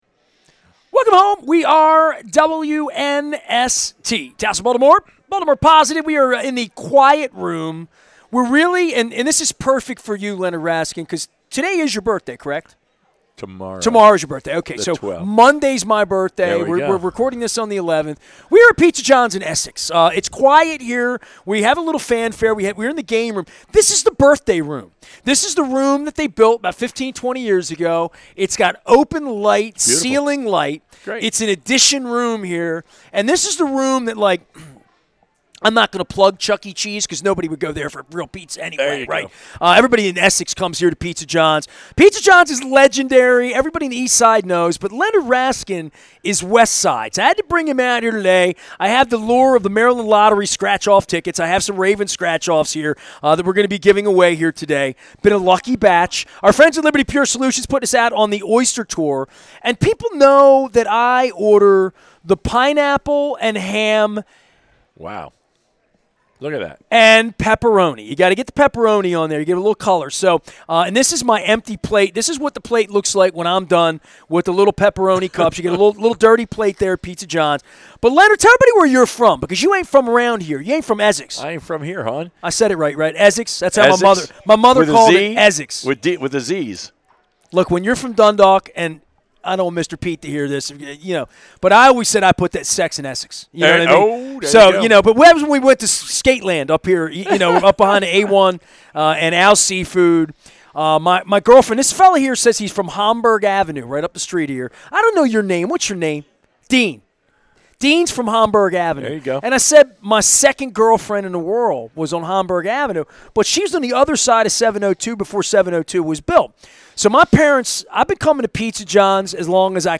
at Pizza John's on the Maryland Crab Cake Tour